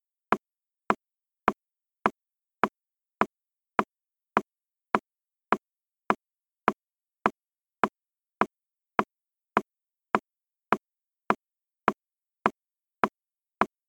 Un día paseando a lo largo del Rio en Santa Eulalia en Ibiza encontré un pequeño Árbol que danzaba con el viento.
Inciso 0 – en 4/4